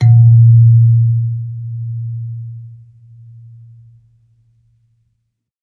kalimba_bass-A#1-pp.wav